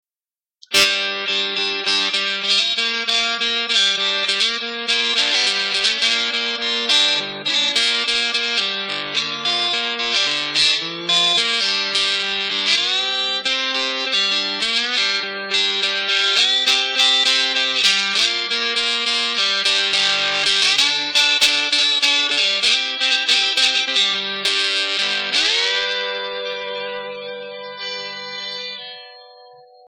Its original tone from its super cheap bar magnet pickups was trashy, but not bad ...except... when I stopped playing sometimes I've noticed that I could hear a local AM radio station through the amp!
The external music links feature this new pickup configuration while the media player has a clip from the original pickup.
The sound clip is about 30 seconds of slide playing in open G tuning. I played it using a chrome slide, a metal thumbpick, and my fingers. The "Tube OD" model on my Korg AX1000G served as the amp.
global_slide.mp3